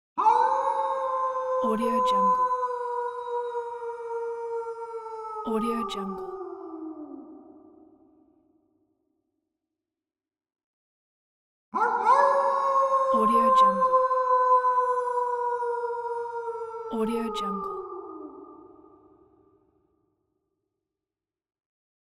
Cartoon Wolf Howl Téléchargement d'Effet Sonore
Cartoon Wolf Howl Bouton sonore